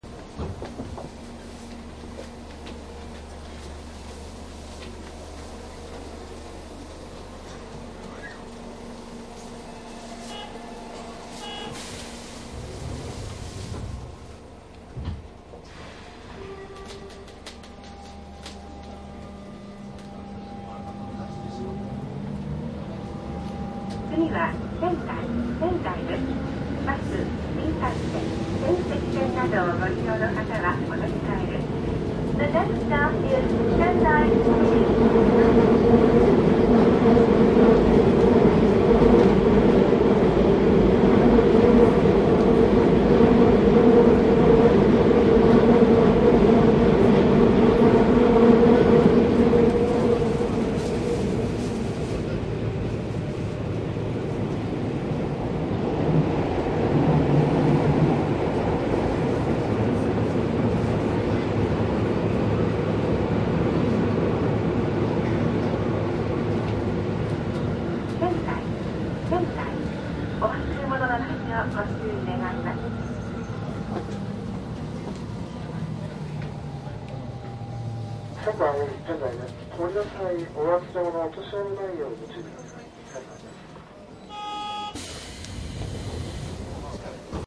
♪仙台地下鉄 鉄道走行音 ＣＤ♪
かなり以前に録音した仙台地下鉄 鉄道走行音 ＣＤです。
地下鉄のチョッパ制御の車両で録音
マスター音源はデジタル44.1kHz16ビット（マイクＥＣＭ959A）で、これを編集ソフトでＣＤに焼いたものです。
収録された音源の車内における乗客の会話などに問題があってもクレームは受付致しません。